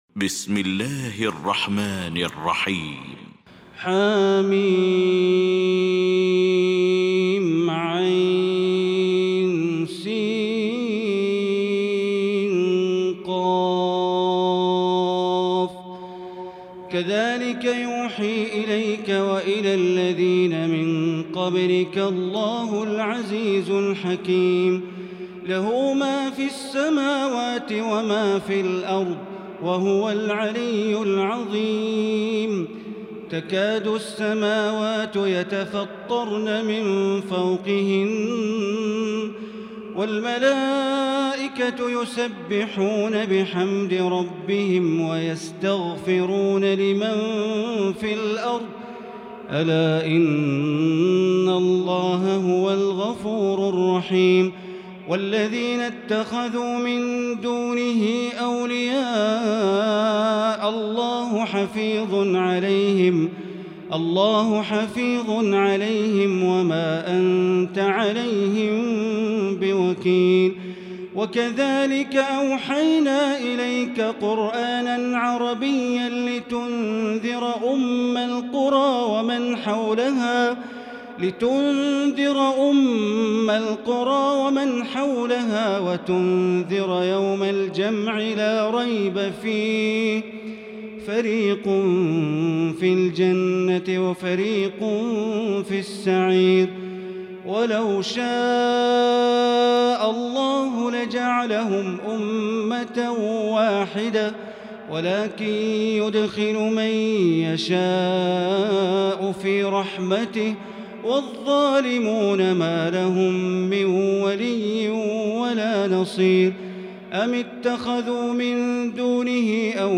المكان: المسجد الحرام الشيخ: معالي الشيخ أ.د. بندر بليلة معالي الشيخ أ.د. بندر بليلة الشورى